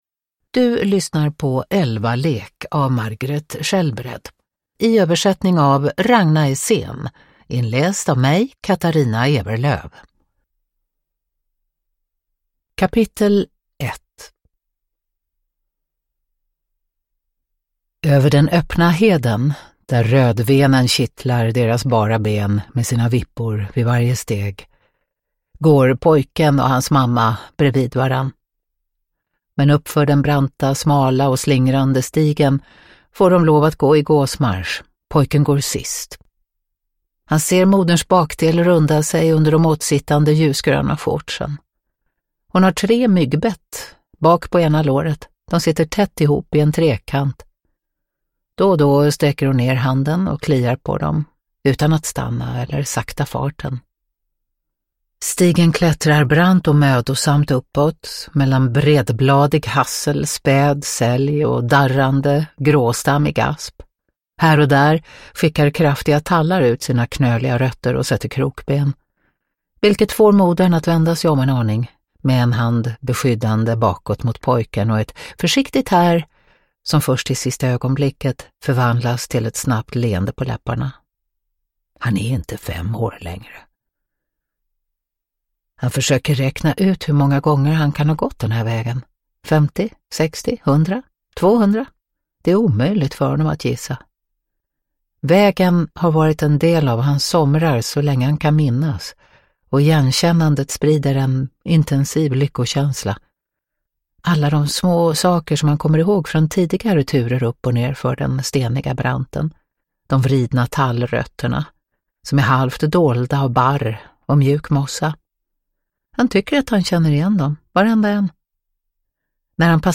Älvalek – Ljudbok – Laddas ner
Uppläsare: Katarina Ewerlöf